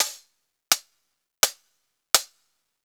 Closed Hats
HIHAT_LIFE_AFTER_DEATH.wav